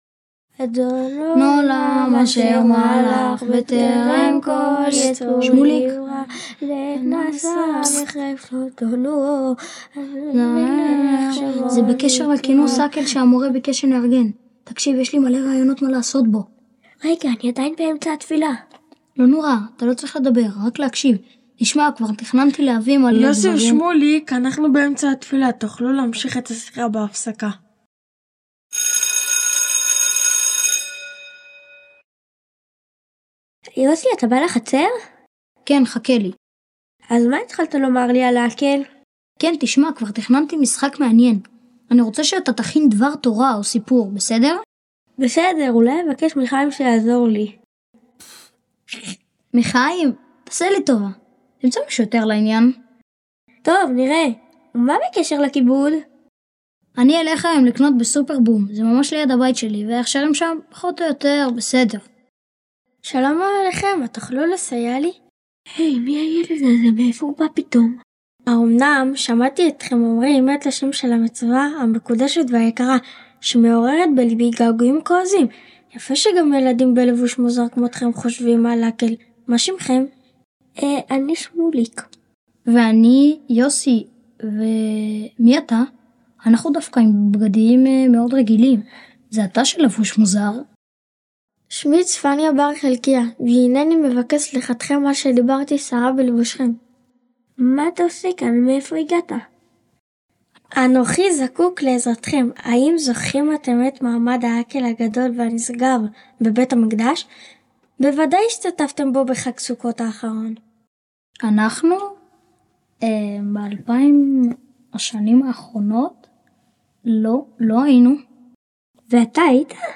פס קול הצגה - תלמודי תורה